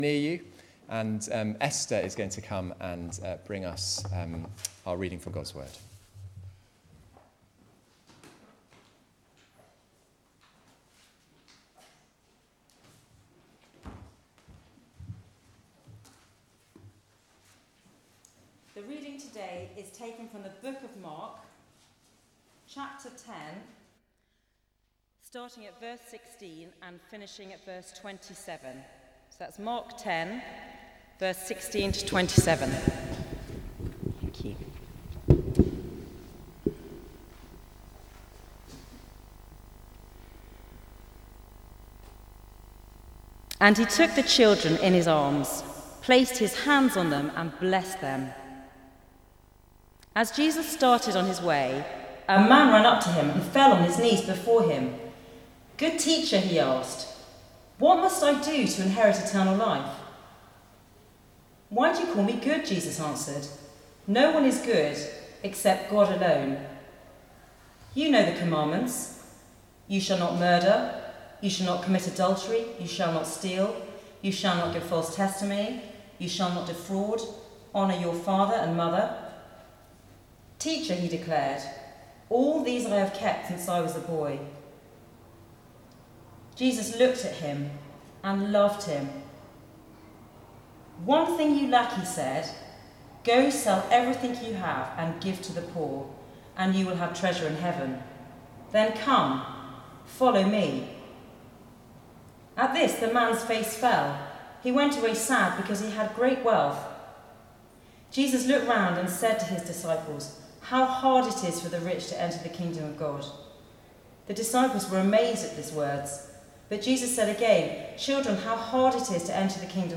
Passage: Mark 10:16-27 Service Type: Weekly Service at 4pm